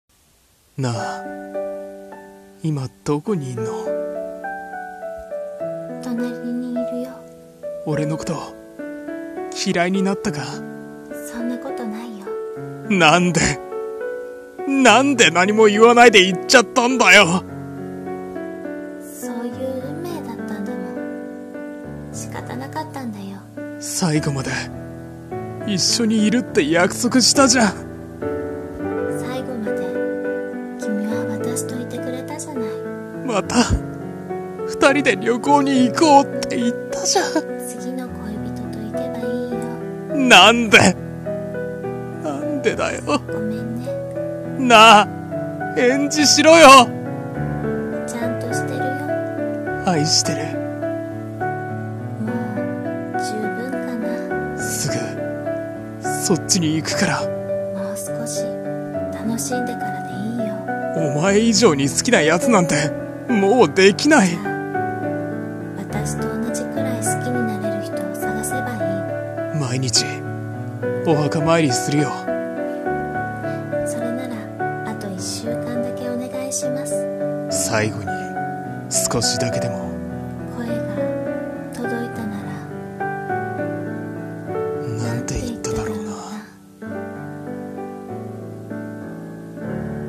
二人声劇/最後の最後に